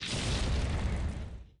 119-Fire03.opus